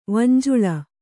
♪ vanjuḷa